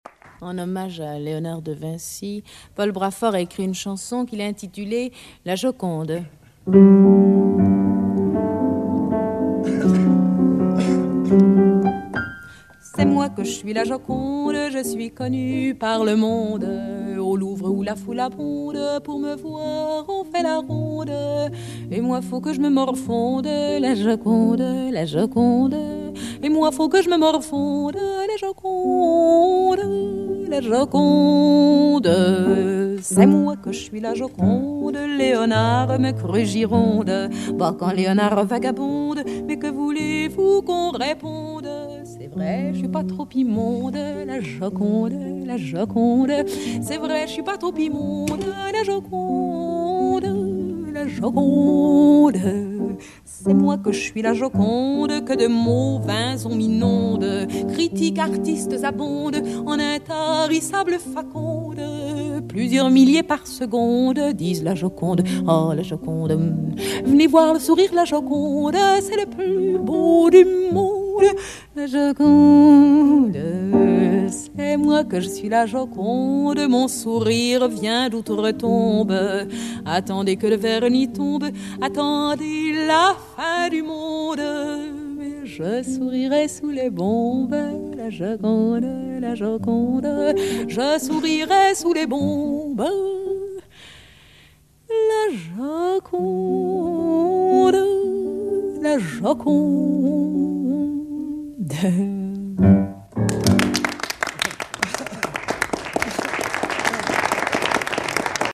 대중 앞에서 녹음한 것.